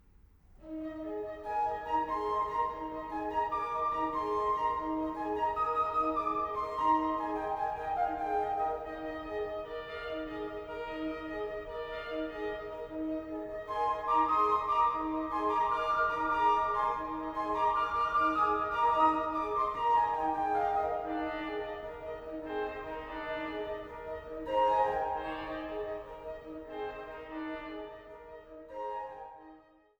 Stellwagen-Orgel